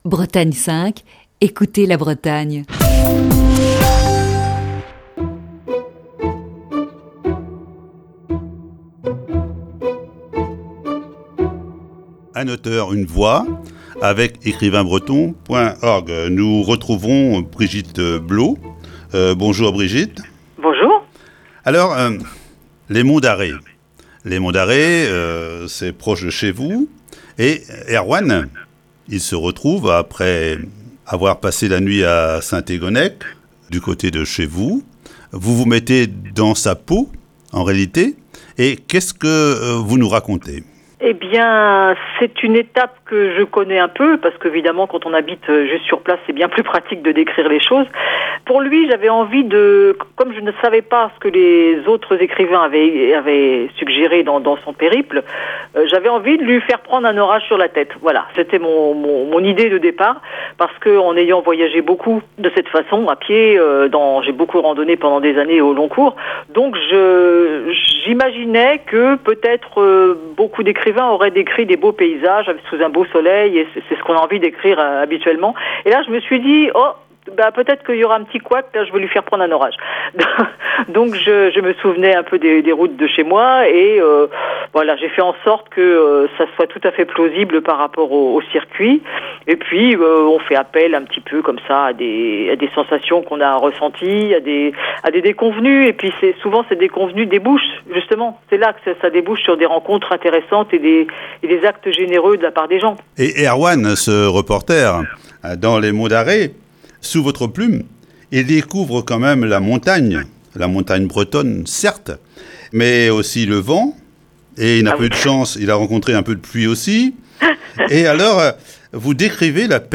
Chronique du 30 mars 2021.